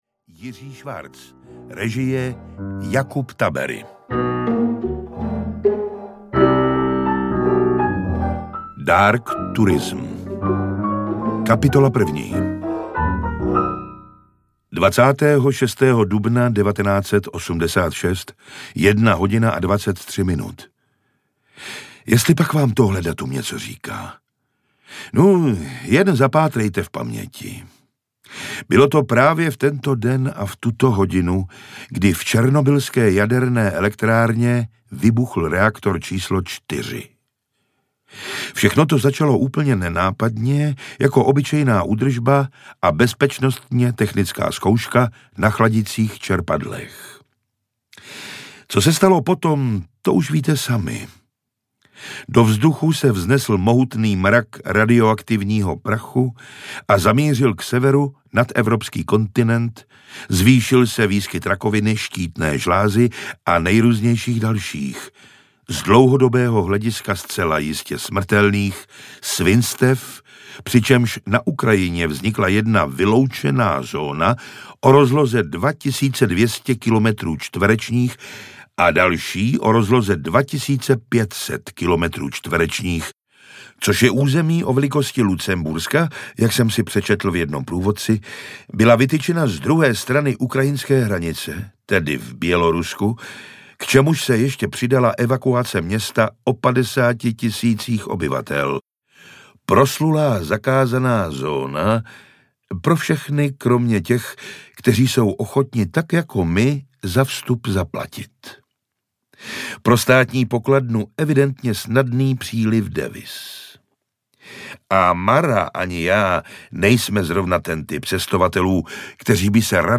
Kočky a 14 dalších povídek audiokniha
Ukázka z knihy